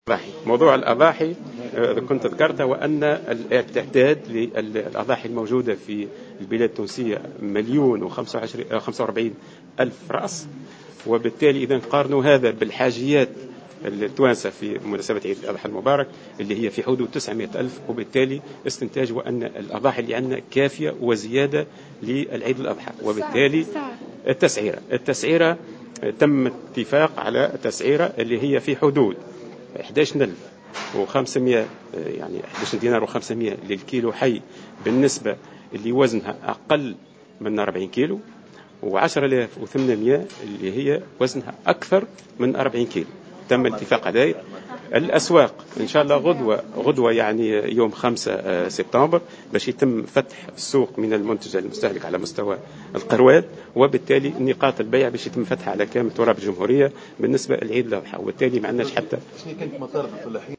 أكد وزير الفلاحة سعد الصديق في تصريح اعلامي اليوم الجمعة 4 سبتمبر 2015 أن عدد الأضاحي الموجودة هذه السنة مليون و45 ألف رأس وهو عدد كافي لتلبيات حاجيات المواطن من الأضاحي التي لاتتجاوز 900 ألف رأس وفق قوله.